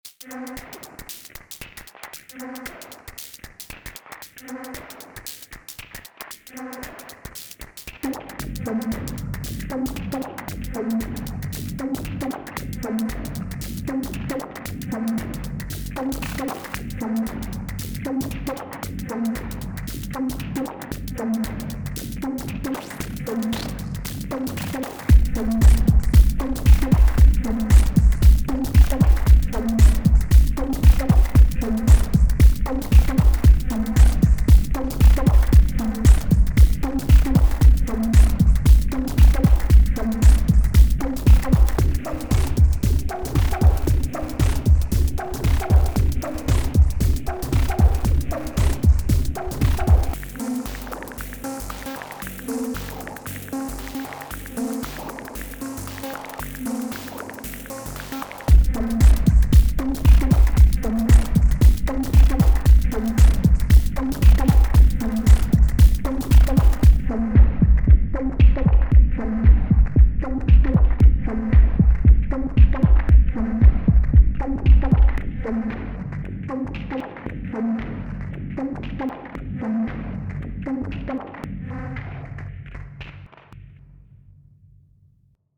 5x Swarm + FX track. Patterns A05 A06 & A07
Again, lots of downtuning and picking out resonances on the noise modulation and lots of p-locking again :smiley: Bit of muting and unmuting during recording.